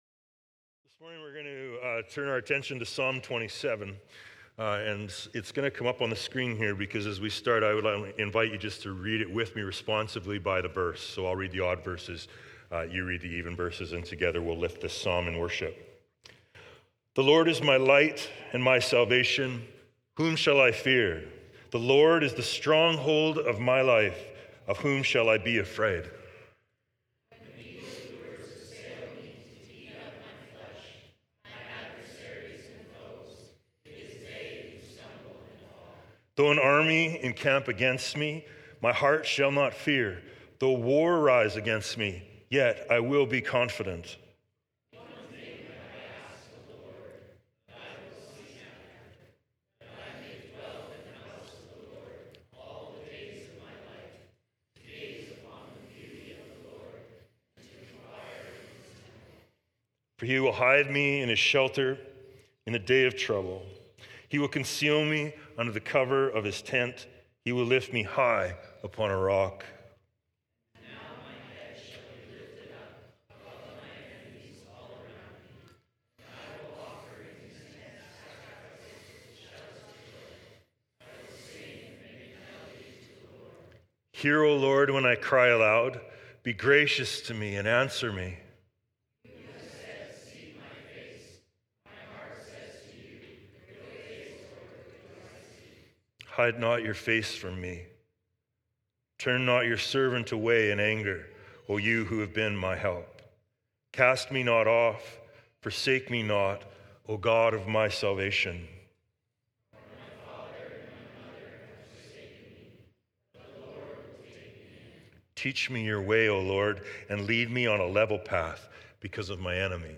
Sermons | Emmaus Road Anglican Church